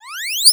maximize_005.ogg